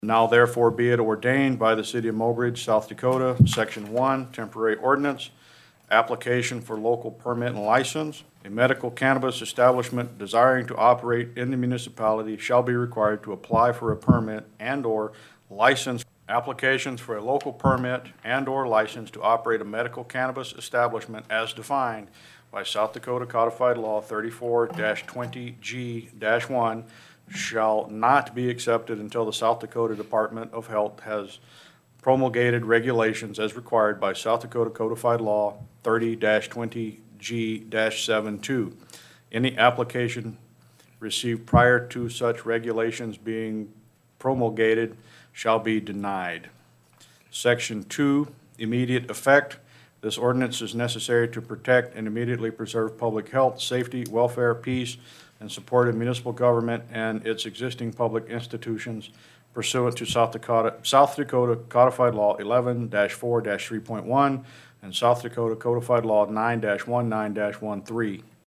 Mayor Cox read Sections One and Two of the ordinance.